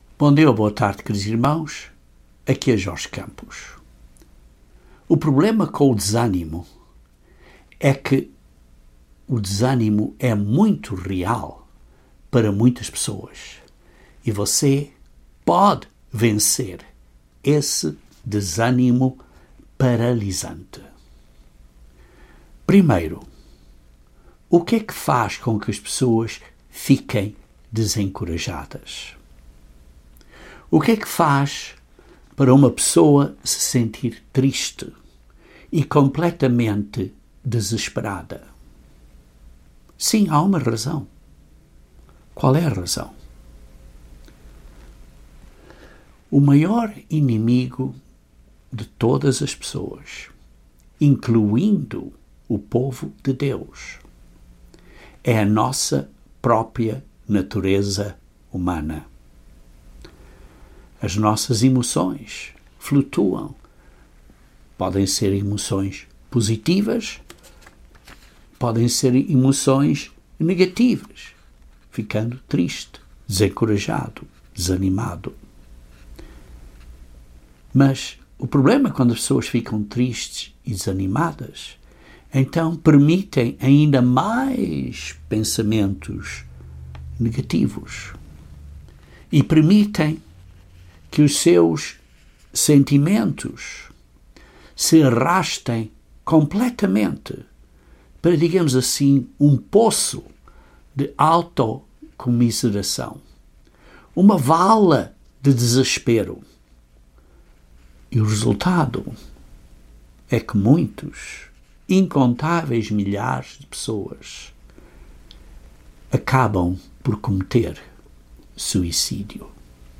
Este sermão aborda princípios de como mudar nossos pensamentos negativos e como podemos aprender a dominá-los.